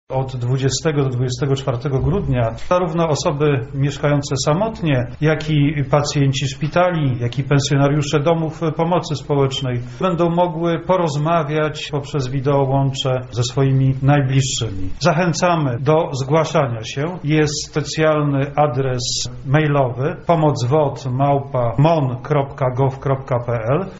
• wyjaśnia Minister Obrony Narodowej Mariusz Błasczak.